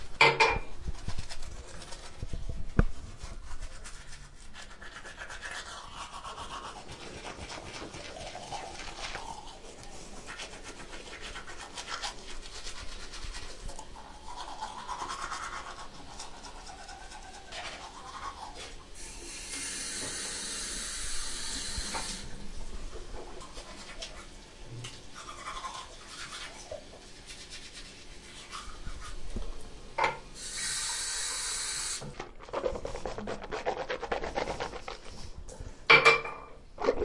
刷牙，日常生活。用Zoom H1录音机录制。